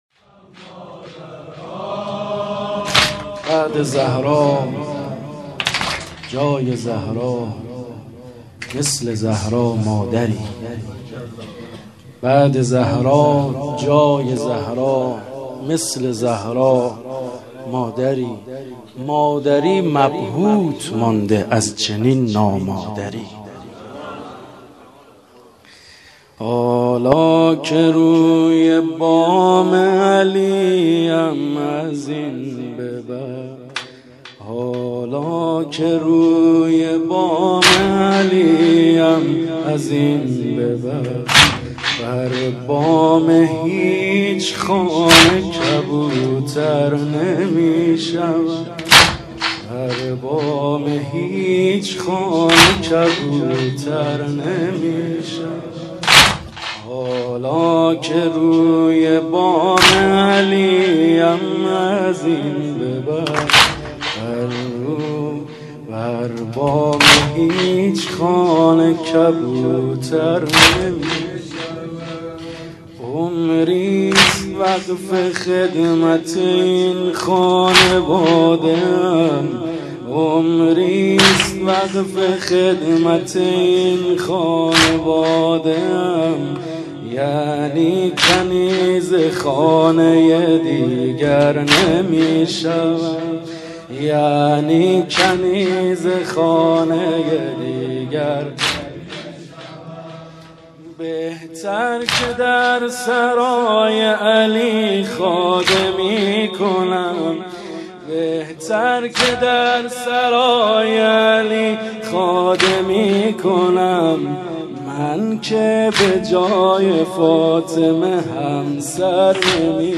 مدح امام حسن